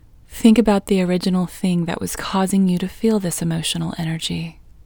IN Technique First Way – Female English 22